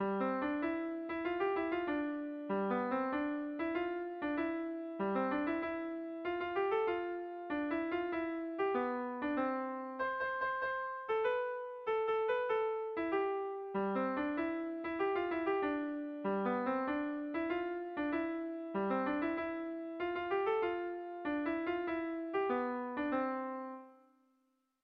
Bertso melodies - View details   To know more about this section
Hamarrekoa, handiaren moldekoa, 6 puntuz (hg) / Sei puntukoa, handiaren moldekoa (ip)
ABDEAB